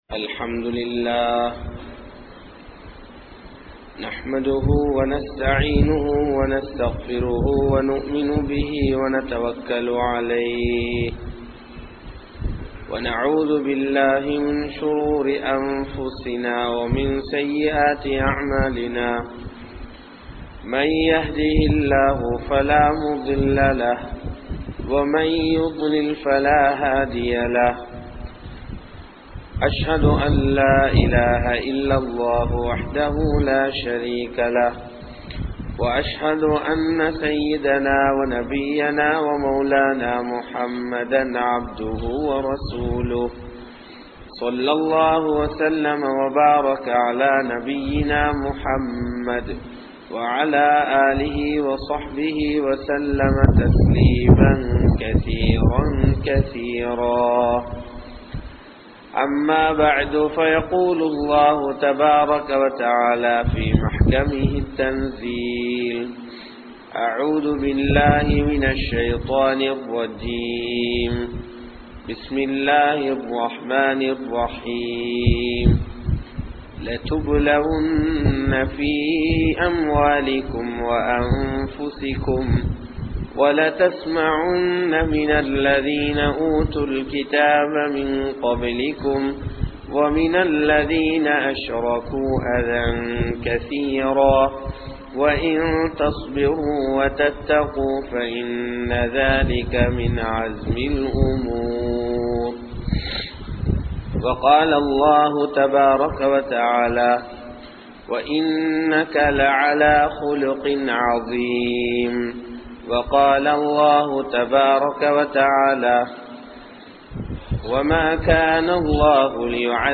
Naam Seium Paavangalin Vilaivu (நாம் செய்யும் பாவங்களின் விளைவு) | Audio Bayans | All Ceylon Muslim Youth Community | Addalaichenai
Kanampittya Masjithun Noor Jumua Masjith